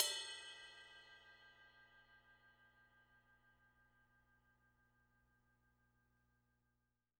R_B Ride Bell 02 - Close.wav